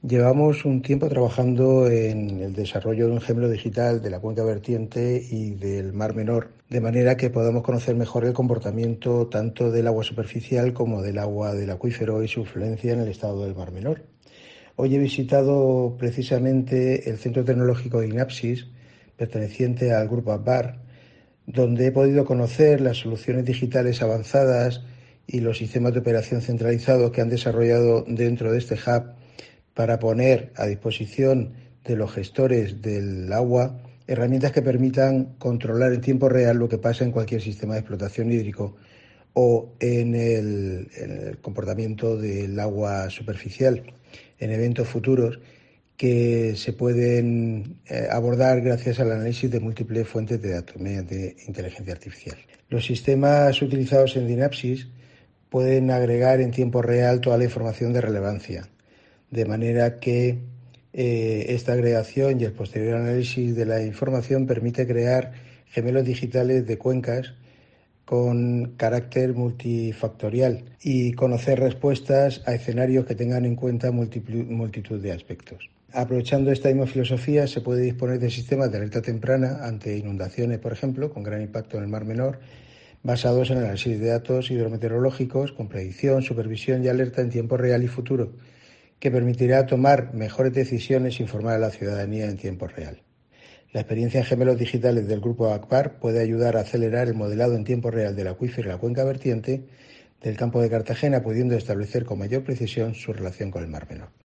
Víctor Serrano, director general del Mar Menor